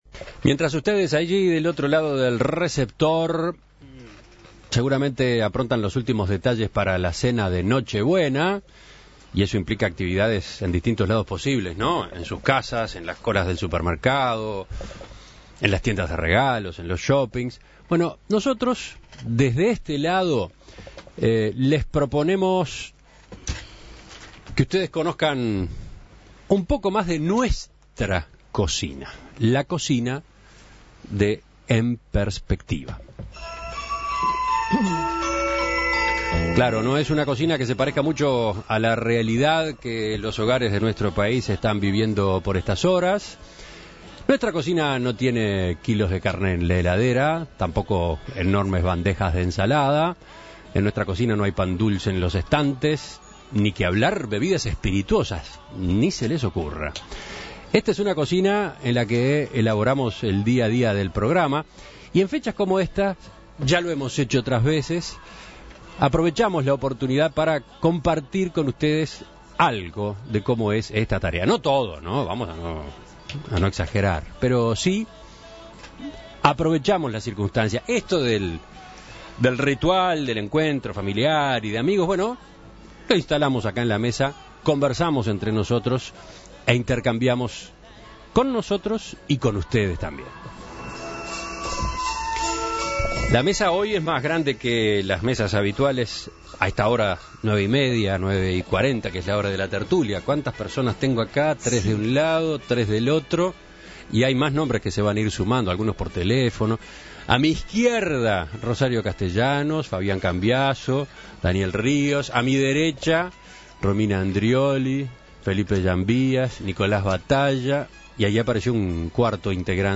El equipo de En Perspectiva copó el estudio para compartir anécdotas con los oyentes en la víspera de Nochebuena
En esta época abundan las reuniones familiares y con amigos, los balances y las metas para el año próximo. En Perspectiva reunió a su equipo en estudio para conversar sobre el trabajo del día a día, presentar a aquellos integrantes que no son tan familiares para la audiencia y compartir algunas anécdotas del programa.